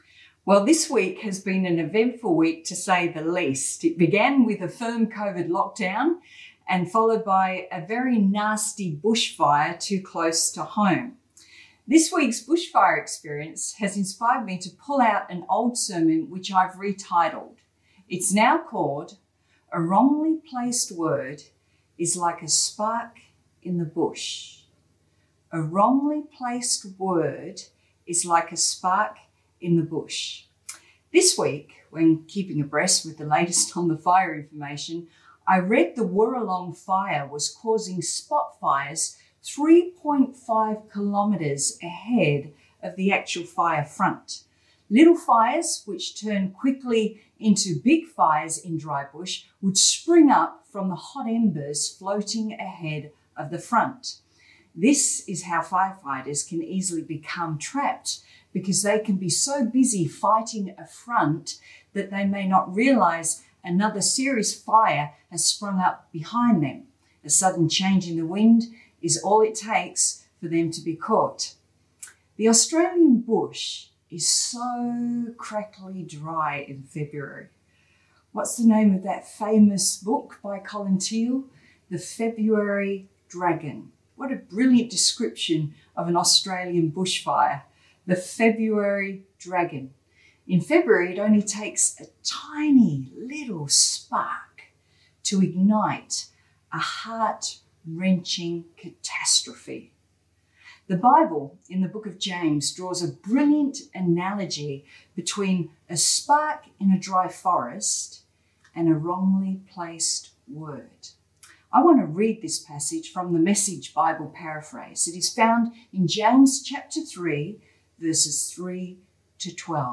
Cityview-Church-Sunday-Service-A-wrongly-placed-word-is-like-a-spark-in-the-bush.mp3